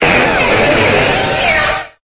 Cri_0477_EB.ogg